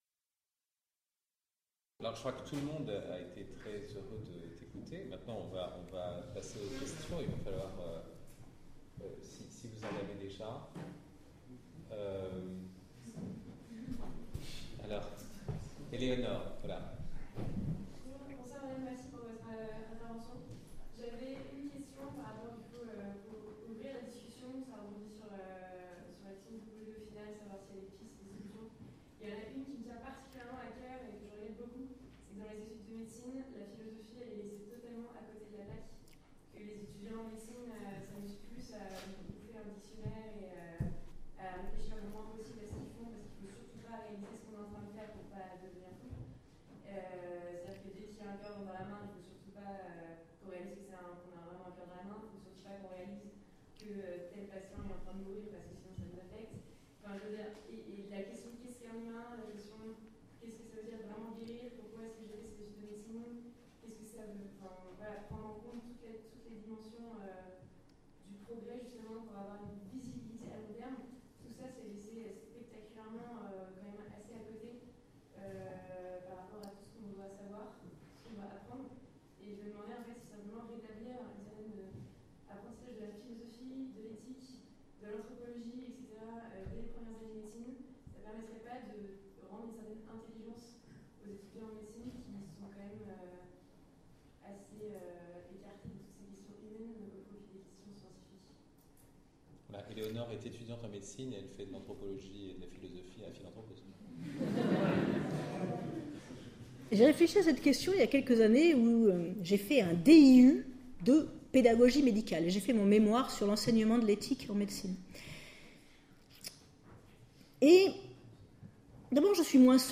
Quel progrès pour la médecine aujourd’hui ? - Questions-réponses